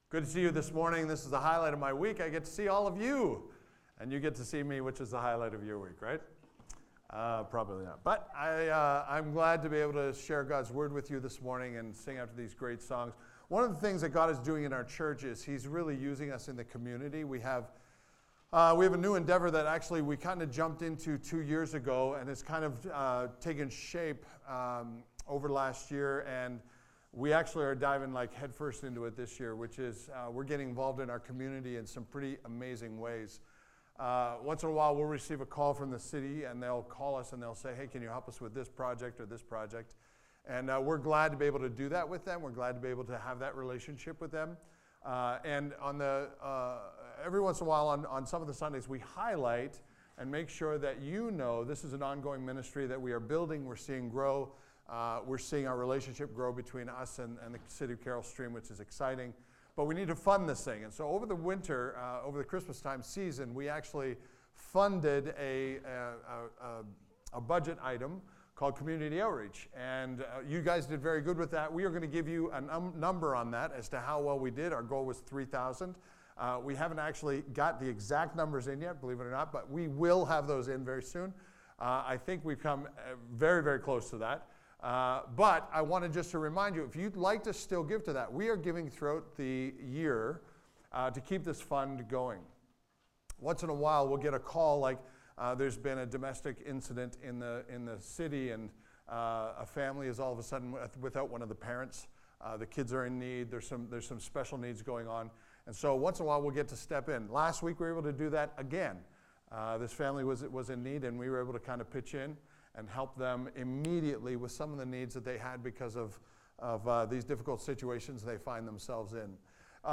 This Sunday, we dove deeper into how the world often distracts us and allows life to become overwhelming. God's power is, and will always be, greater than any demonic power there is, becaus He is God, the one who protects and fights for us.